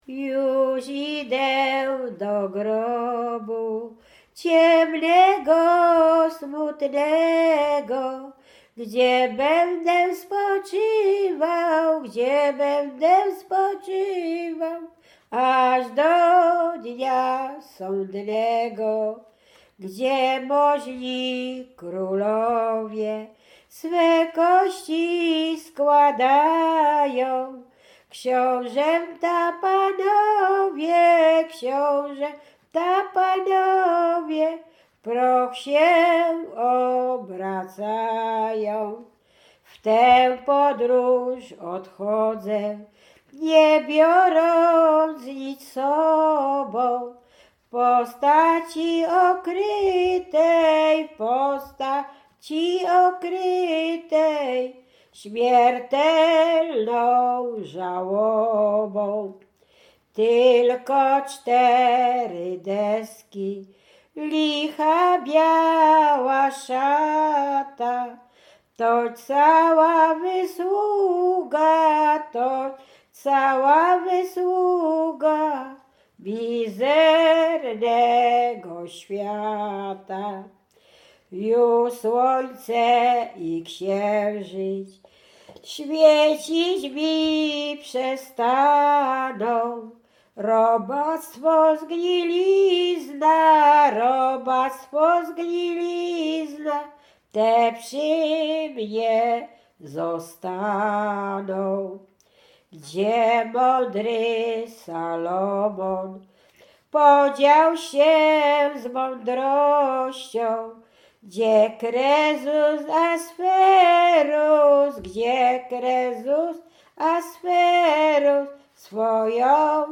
Łęczyckie
Pogrzebowa
pogrzebowe nabożne katolickie do grobu